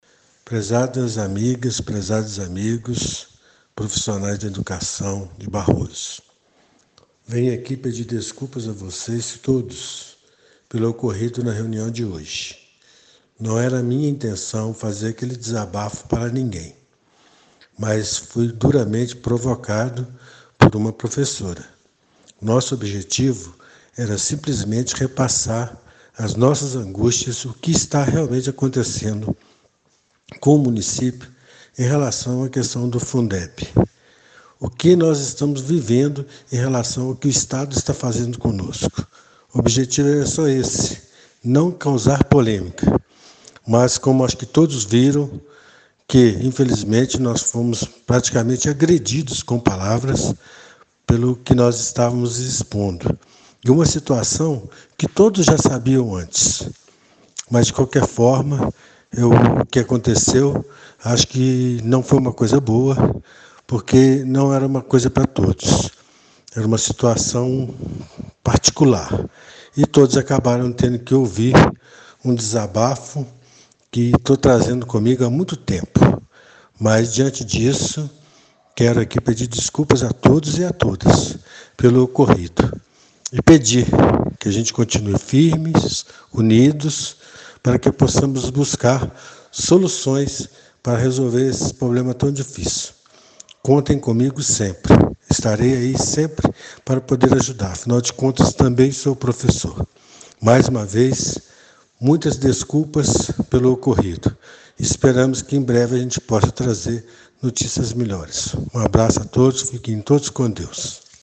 Através de um áudio via WhatsApp, o Prefeito pediu desculpas aos presentes.